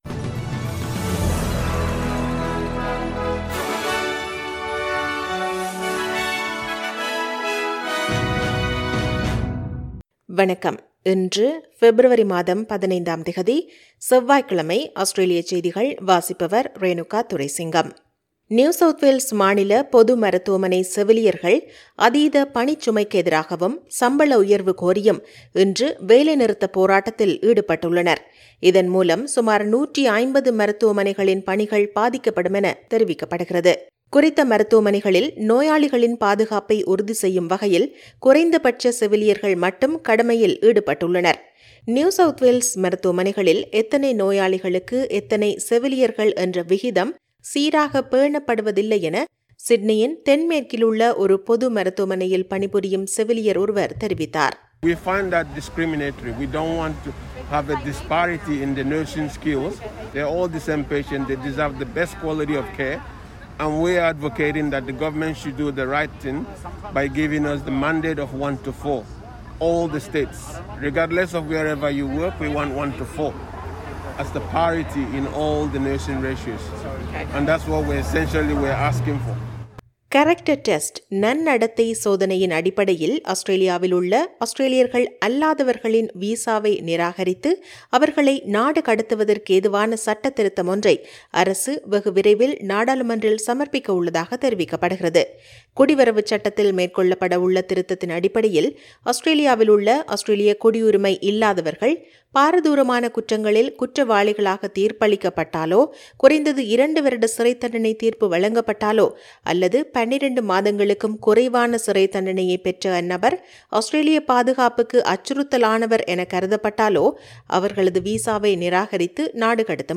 Australian news bulletin